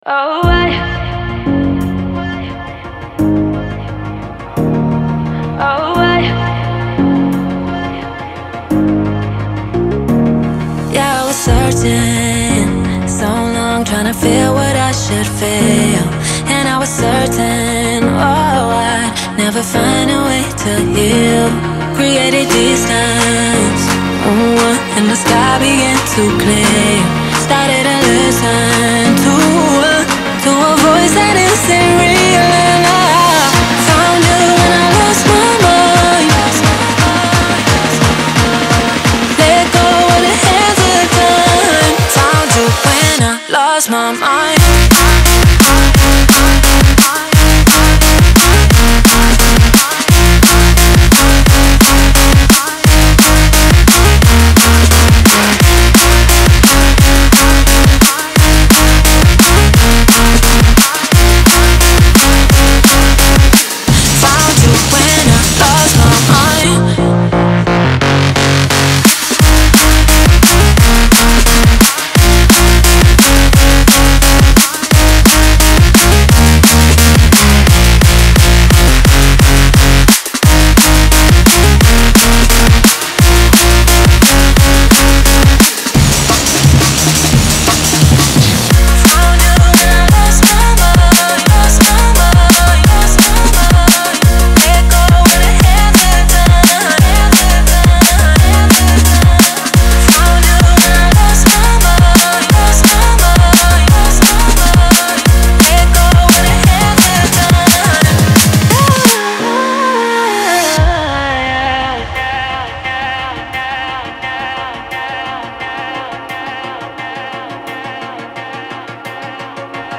• Жанр: Dram&Bass